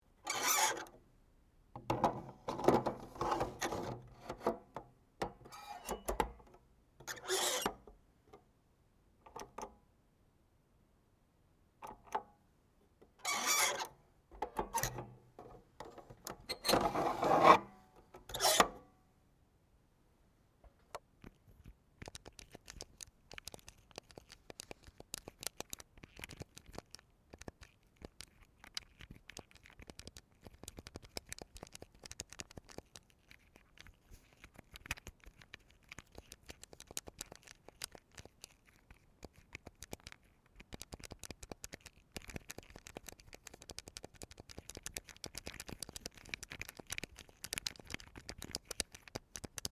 Nintendo NES game console